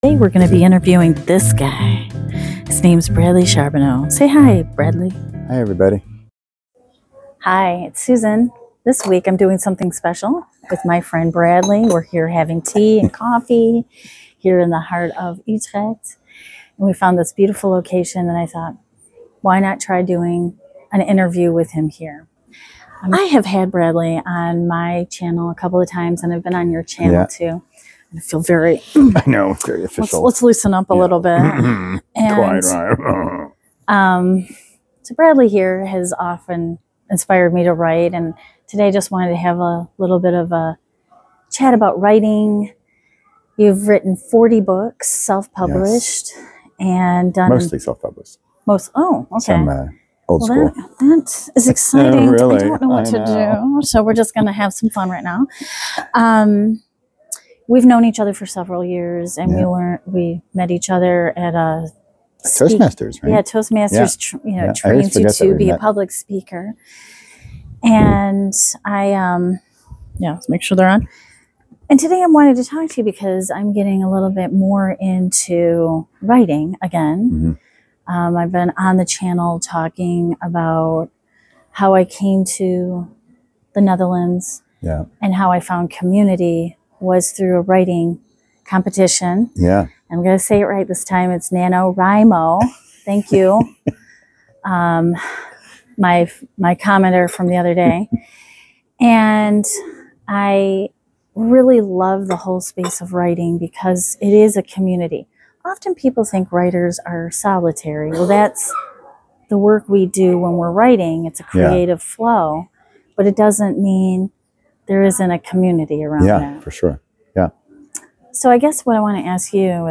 A conversation about fear, perfectionism, creative practice, and giving yourself the dream.